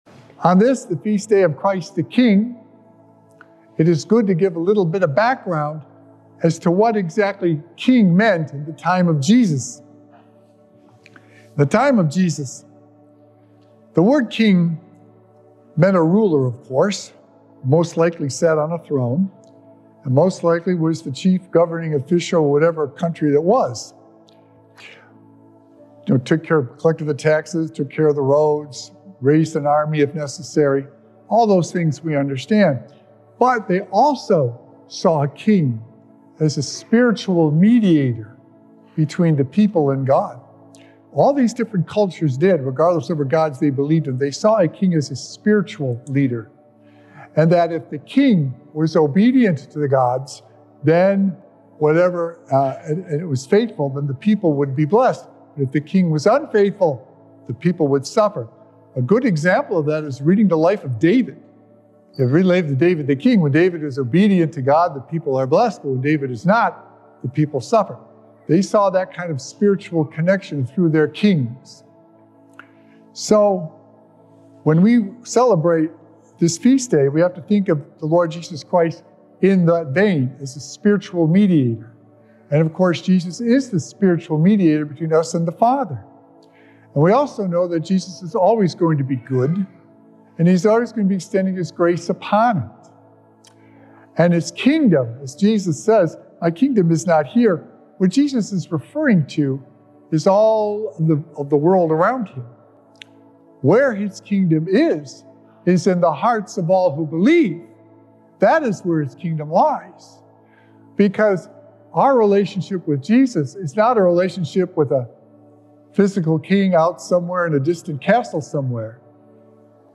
Jesus IS King, and He mediates by connecting us to His Father and His Saving Grace! Recorded Live on Sunday, November 24th, 2024 at St. Malachy Catholic Church.
Weekly Homilies